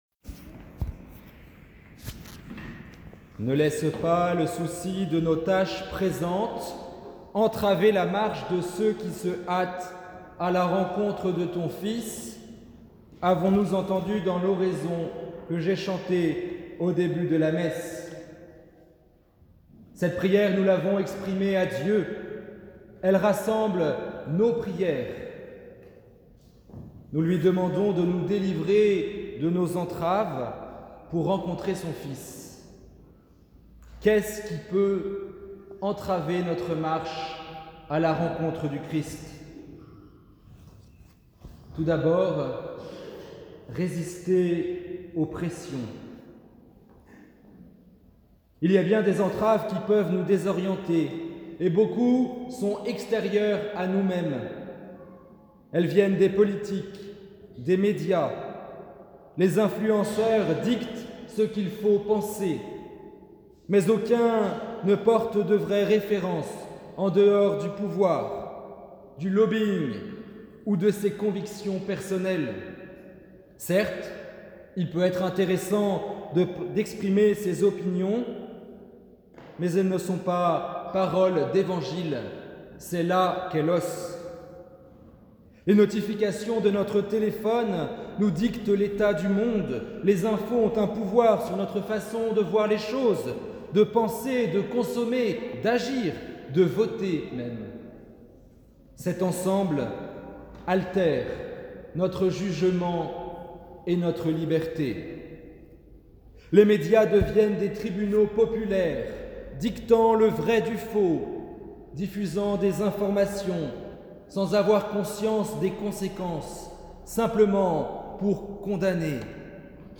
Homélie du 2eme dimanche de l’Avent – 4 et 5 décembre 2021 – (Lectures : Ba 5, 1-9/ Ps 125/Ph 1, 4-6.8-11/ Lc 3, 1-6)
Homelie-2e-dimanche-Avent-annee-C.mp3